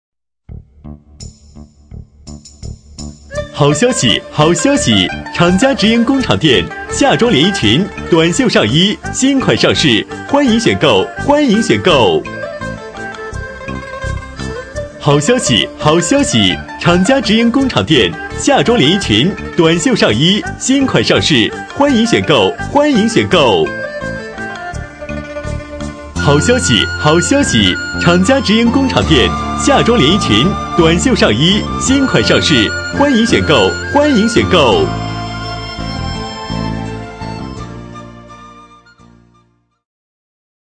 【男31号促销】服装工厂价
【男31号促销】服装工厂价.mp3